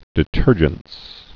(dĭtûrjəns)